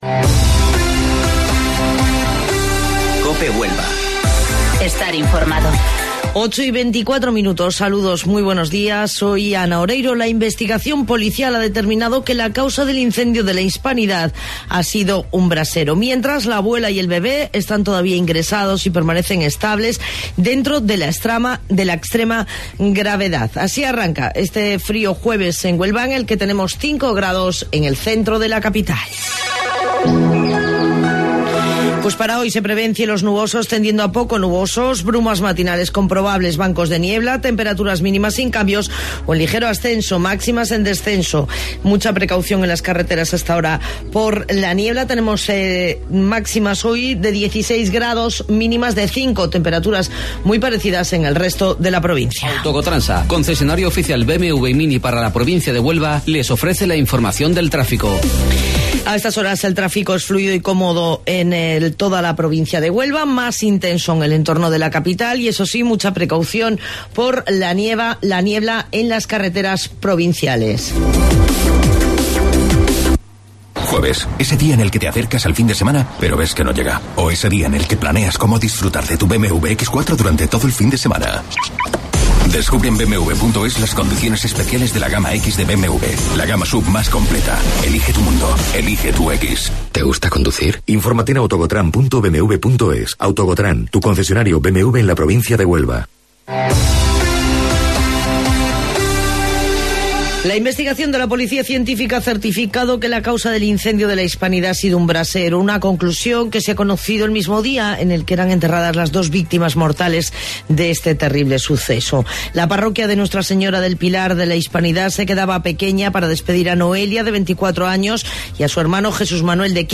AUDIO: Informativo Local 08:25 del 9 Enero